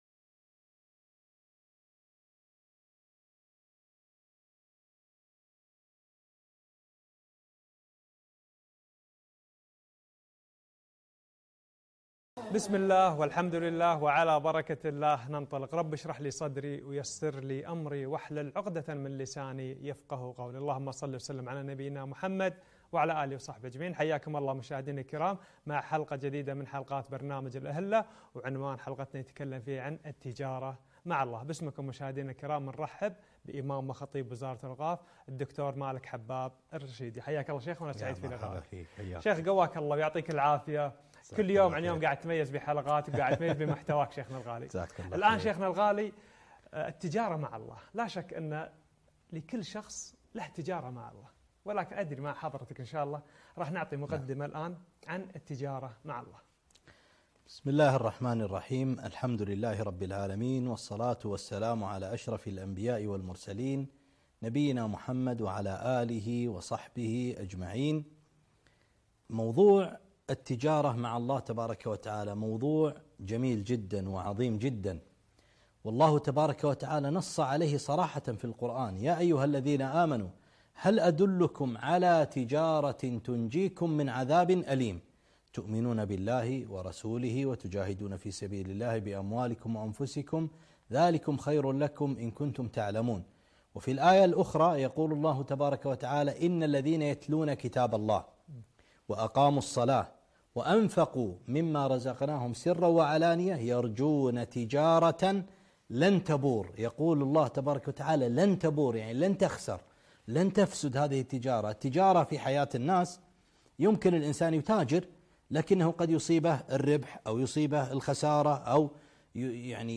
( التجارة مع الله ) - كلمة